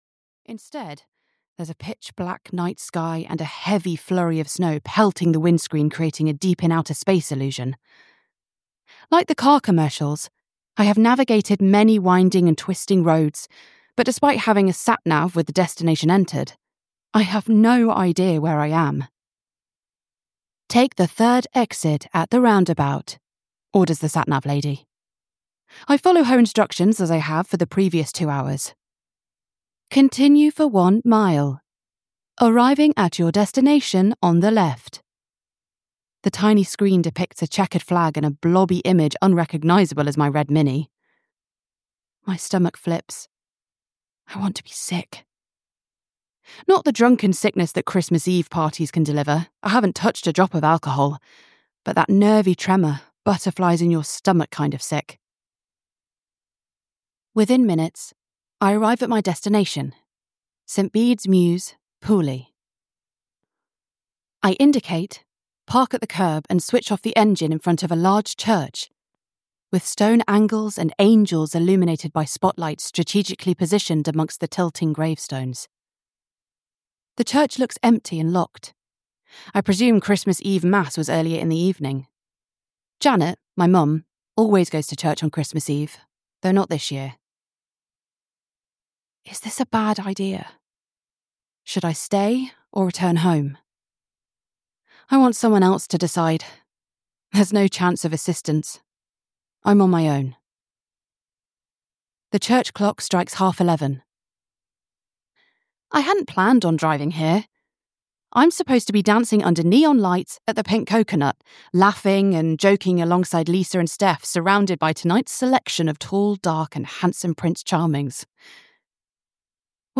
A Christmas Wish (EN) audiokniha
Ukázka z knihy